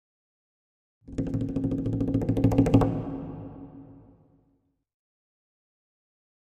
Drums Percussion Danger - Fast Drumming On A Medium Percussion 1